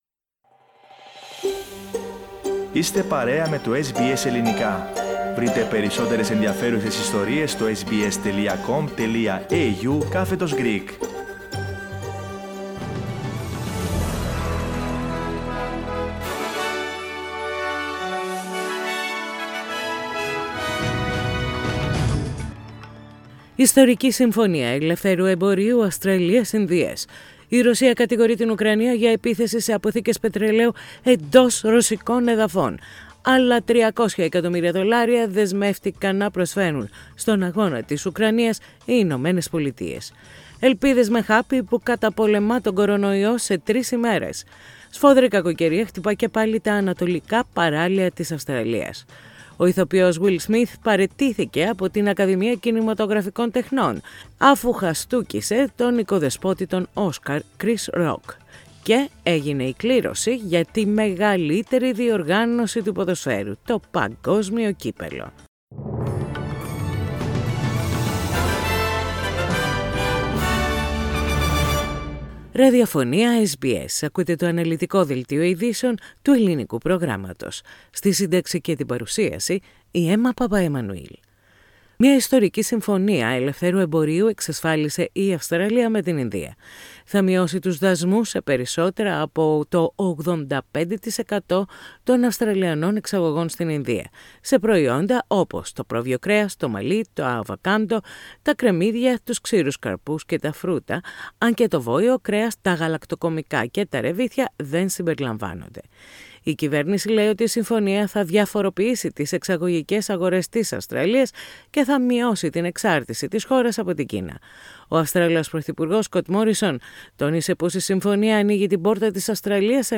Δελτίο ειδήσεων - Σάββατο 2.4.22
News in Greek.